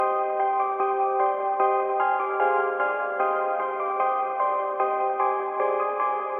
Tag: 150 bpm Trap Loops Piano Loops 1.08 MB wav Key : Unknown